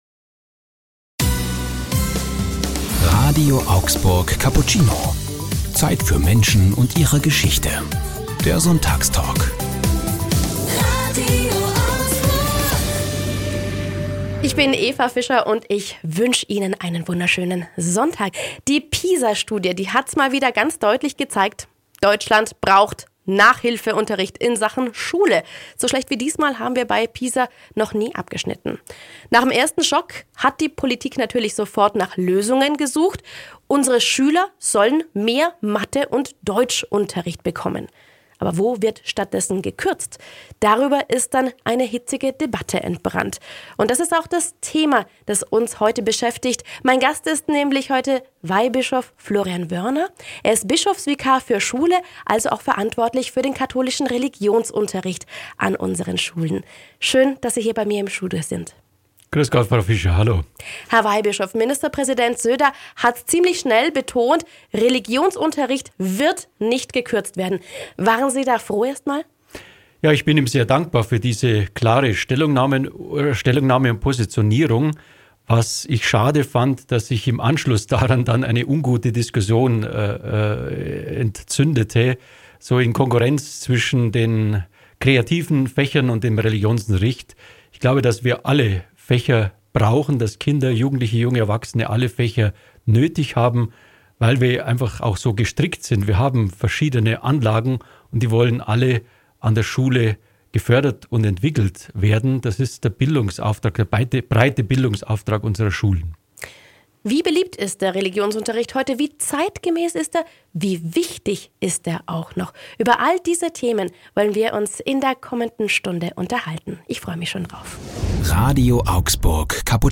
Religionsunterricht, quo vadis? Sonntagstalk mit Weihbischof Wörner ~ RADIO AUGSBURG Cappuccino Podcast